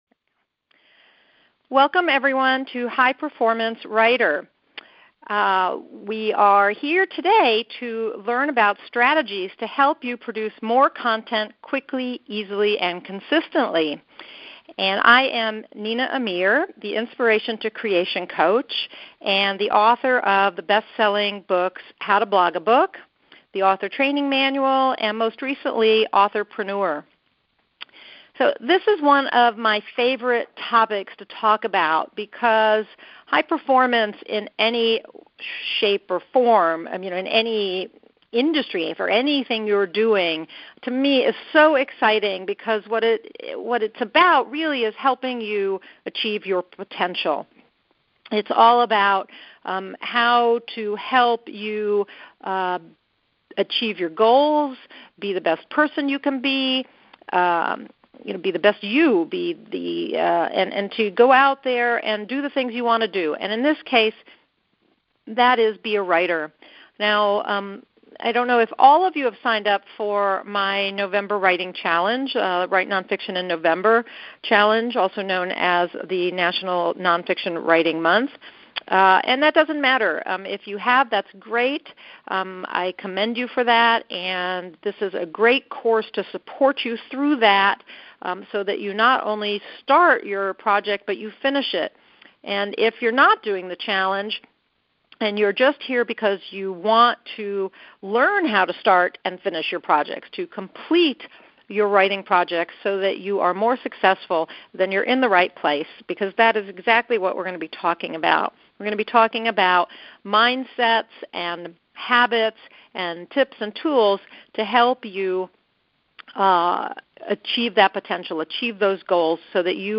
Teleseminar Recording